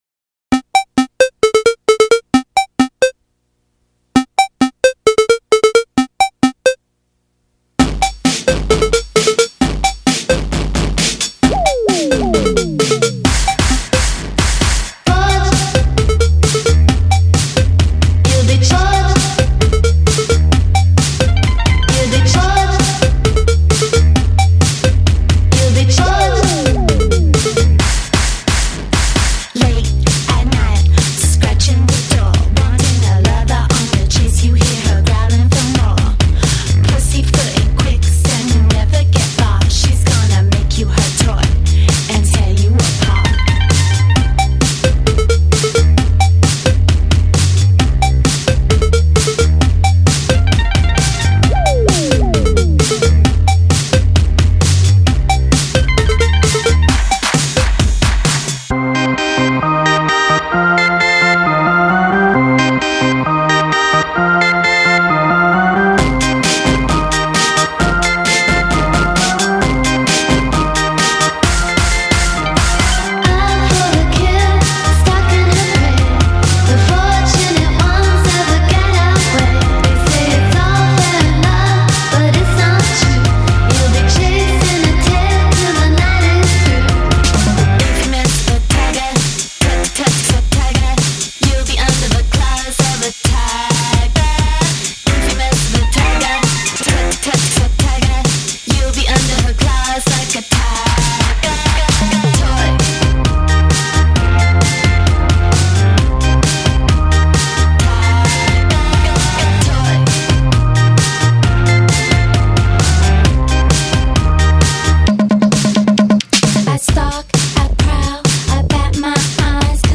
风格：后摇